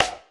Clap Funk 2.wav